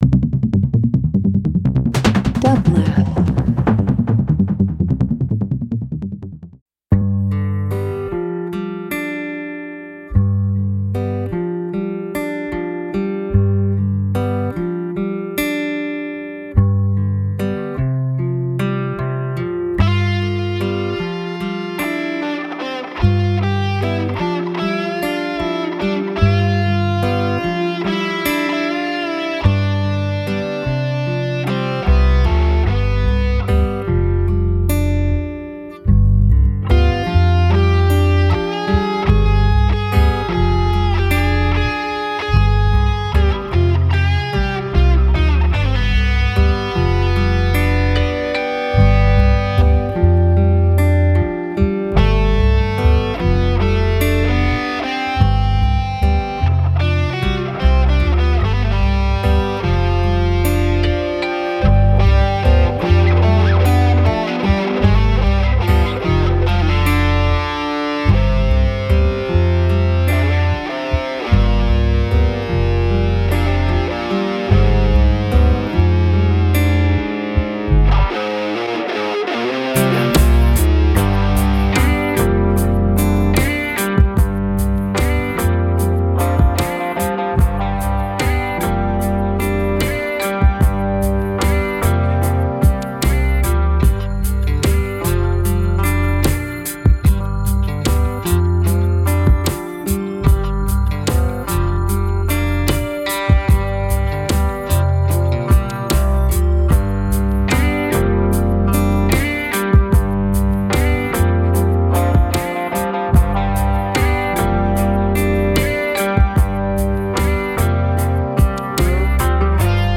German DJ troupe
eclectic wednesdaydub and psychedelic dance tracks
Dance Disco Downtempo